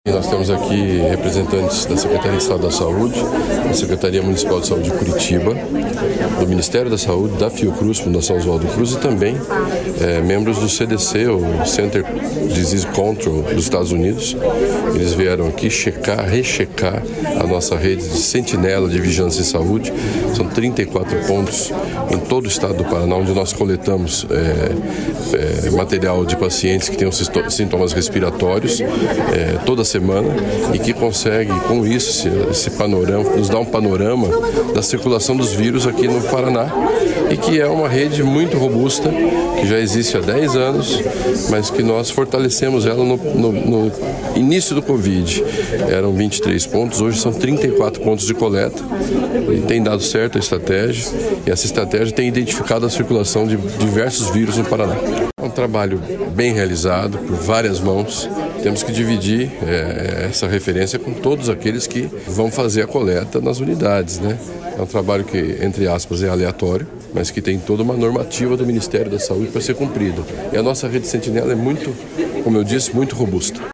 Sonora do secretário da Saúde, Beto Preto, sobre o Paraná ser referência no monitoramento, análise de dados e prevenção das síndromes gripais